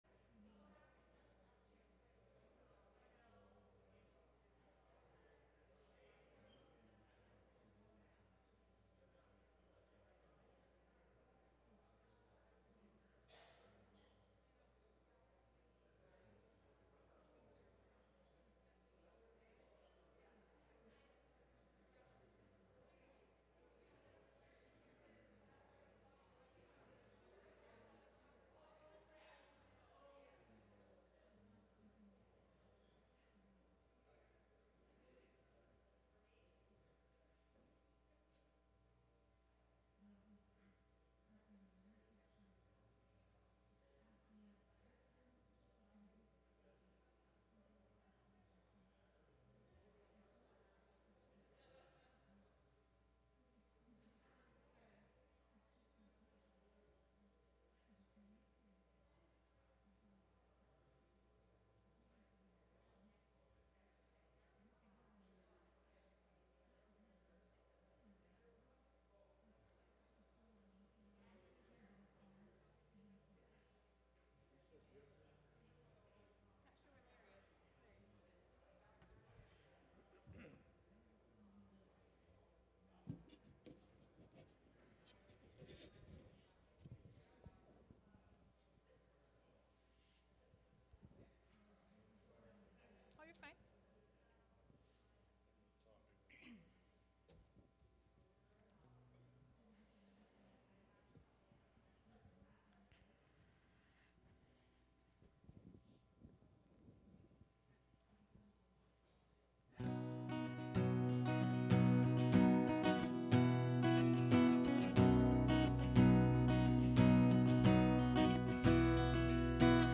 November 1, 2020 (Morning Worship)